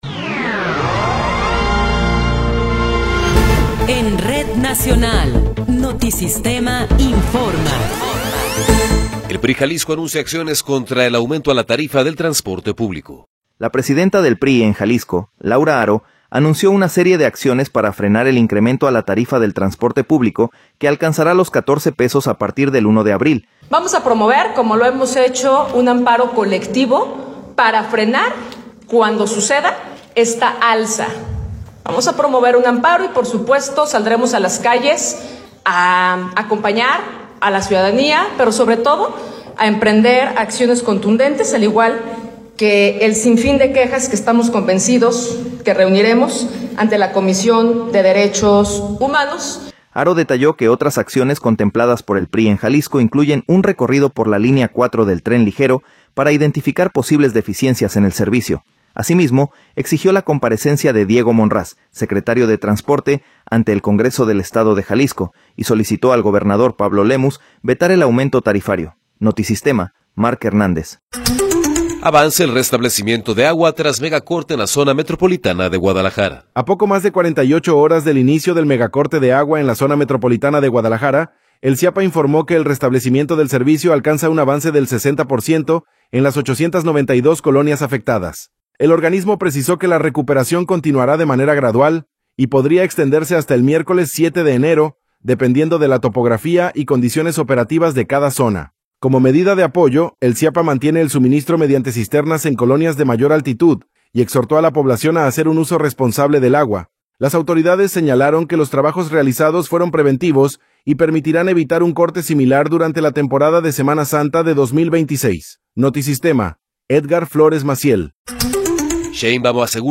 Noticiero 12 hrs. – 5 de Enero de 2026
Resumen informativo Notisistema, la mejor y más completa información cada hora en la hora.